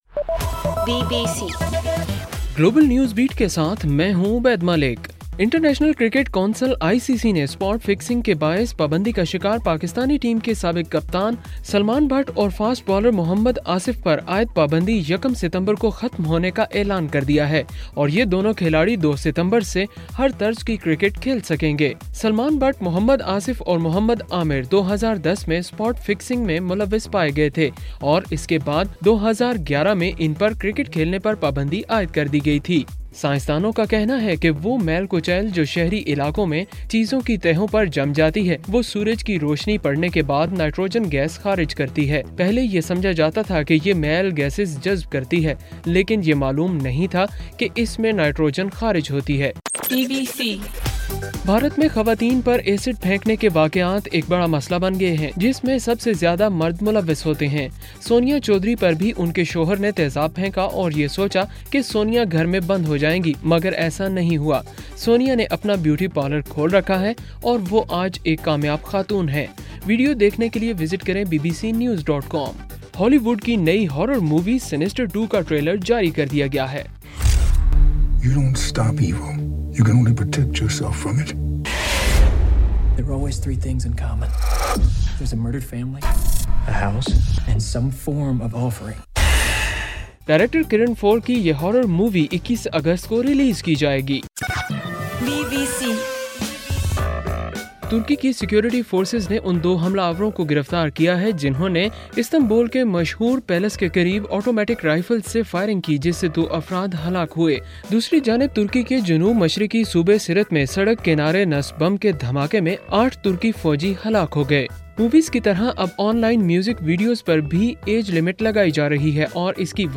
اگست 19: رات 10 بجے کا گلوبل نیوز بیٹ بُلیٹن